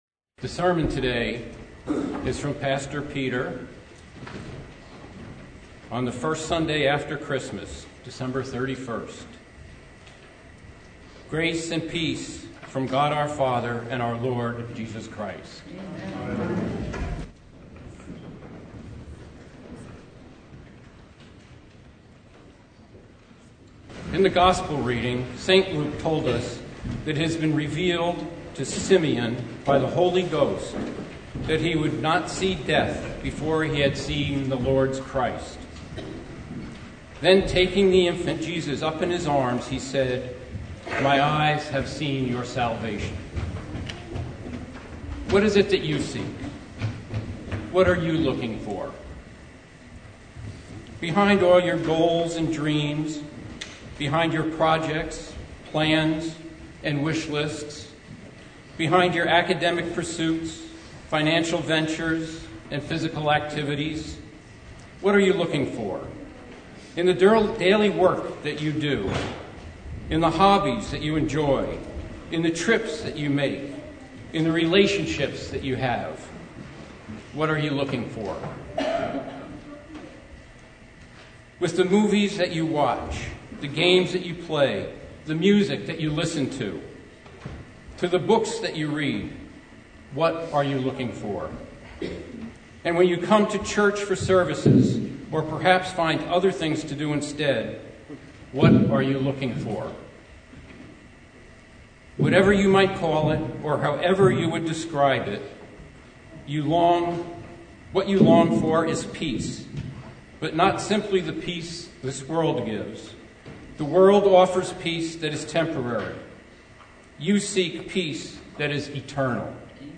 Sermon from First Sunday after Christmas (2023)